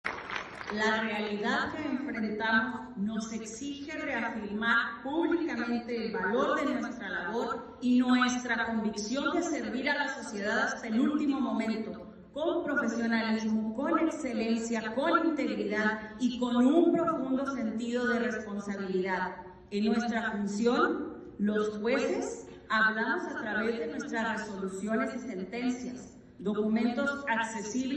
La magistrada presidenta Myriam Hernández presentó su último informe de actividades al frente del Tribunal Superior de Justicia (TSJ) del Estado de Chihuahua en el Centro de Convenciones y Exposiciones de la ciudad. Durante su informe , Hernández destacó que en 2024 se emitieron 4,500 resoluciones más en comparación con el año anterior, además, señaló que el TSJ logró impactar a 18,000 personas a través de programas de justicia restaurativa.
La sesión solemne del pleno del Poder Judicial incluyó honores a la bandera y la presentación de autoridades.